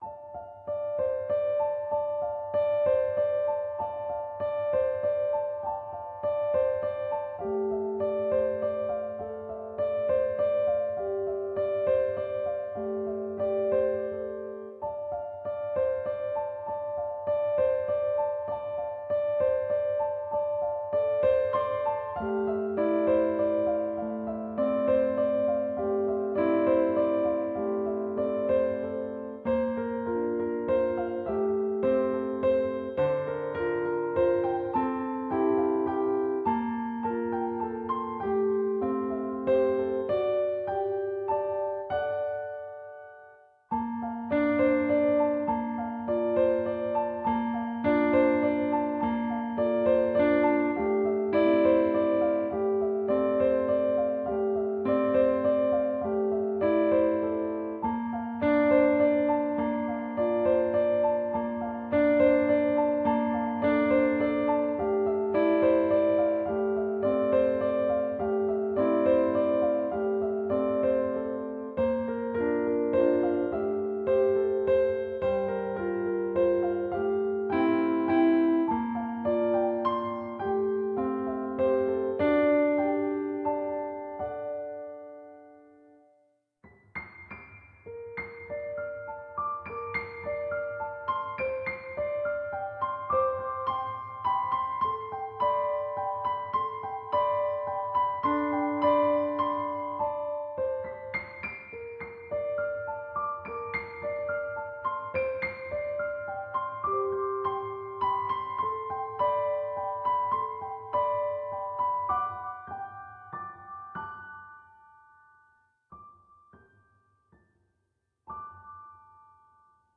本当は途中からピッコロや打楽器が入るのですが，ピアノパートのみの演奏です。